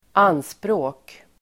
Uttal: [²'an:språ:k]